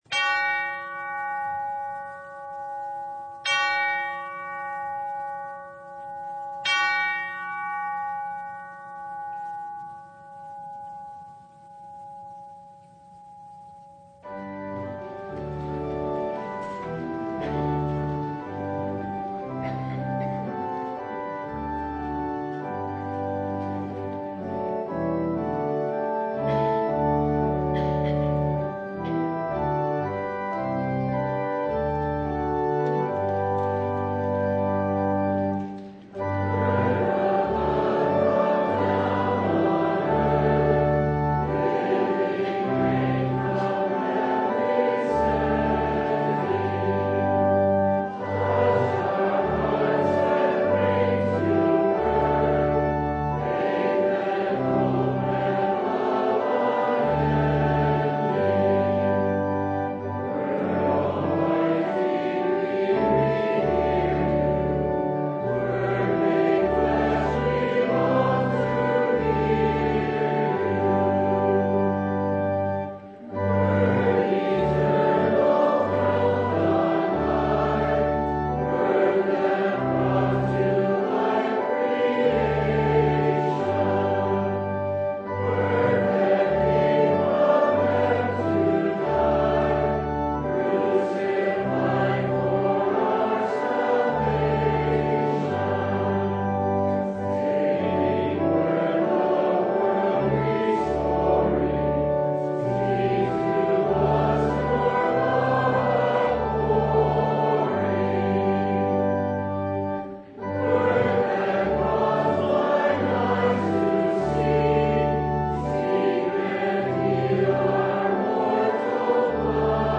Mark 7:24-37 Service Type: Sunday Jesus sticks His finger in our ears and grabs ahold of our tongues